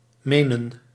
Menen (Dutch pronunciation: [ˈmeːnə(n)]
Nl-Menen.ogg.mp3